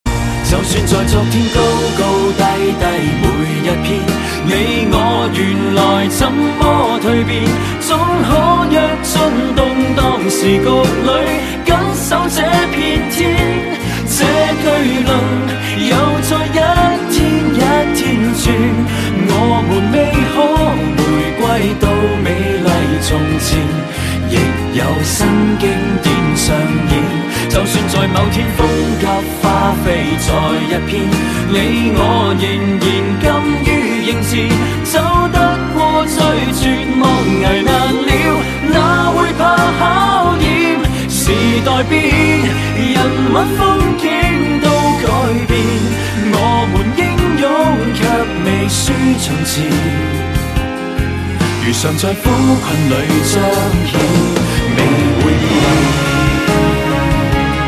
M4R铃声, MP3铃声, 华语歌曲 107 首发日期：2018-05-16 00:21 星期三